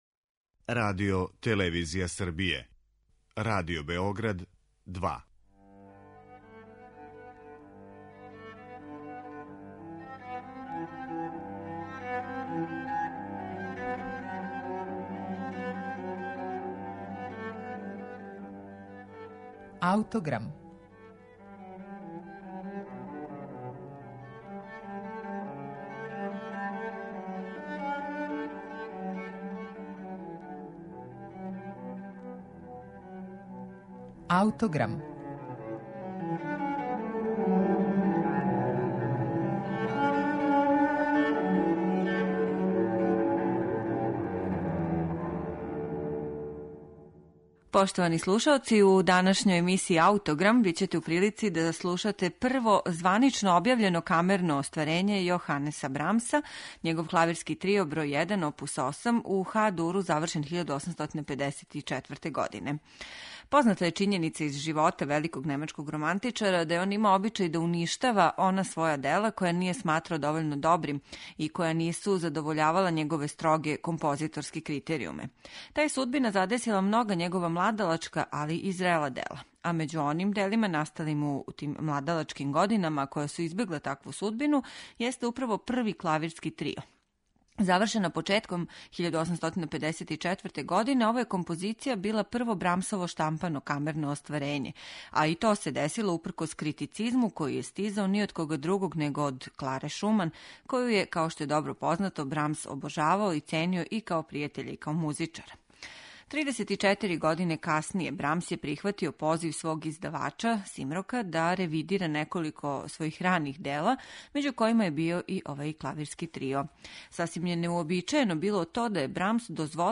Славни немачки романтичар Јоханес Брамс завршио је свој Клавирски трио оп. 8 1854. године.